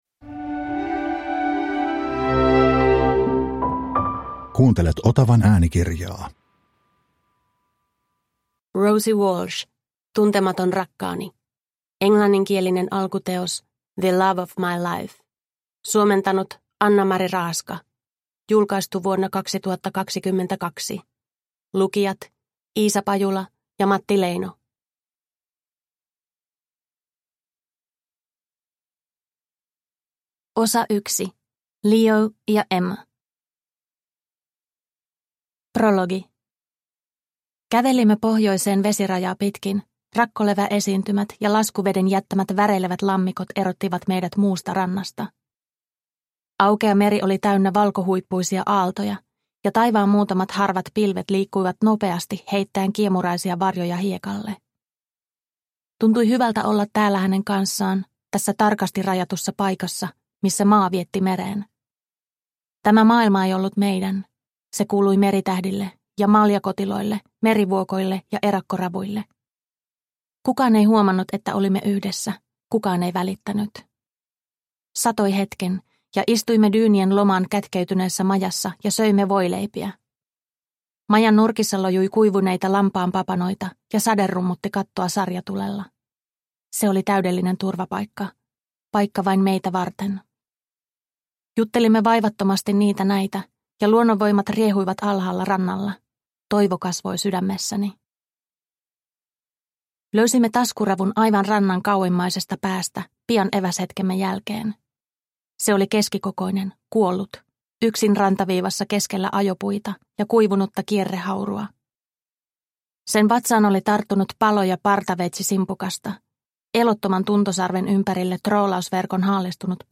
Tuntematon rakkaani – Ljudbok – Laddas ner